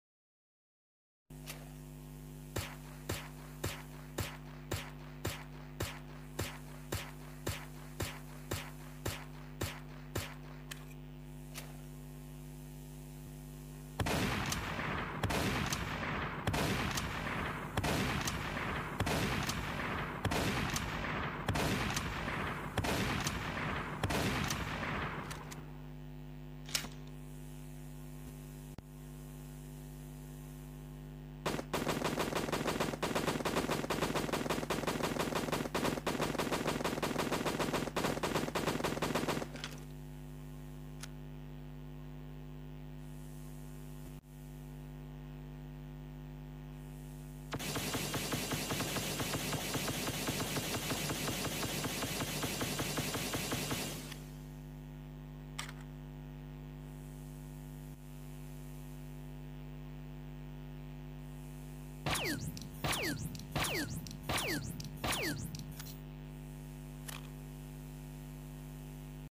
All Weapons From The Game Sound Effects Free Download